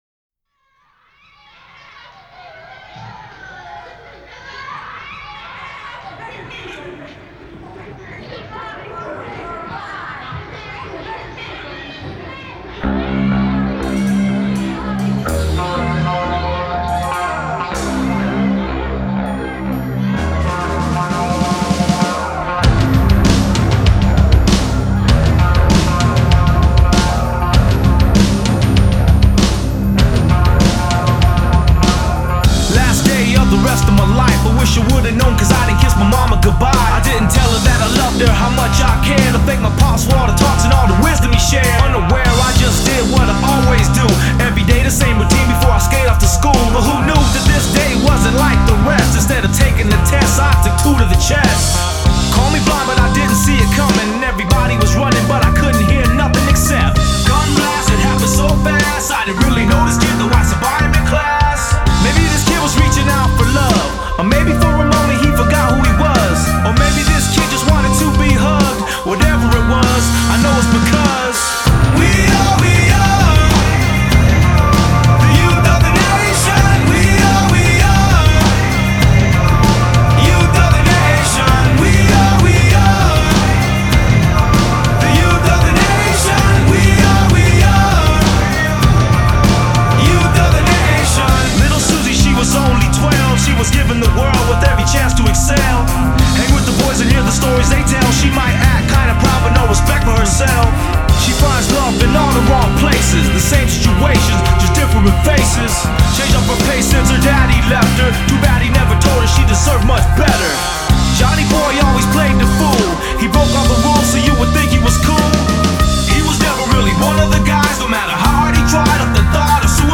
Жанр: numetal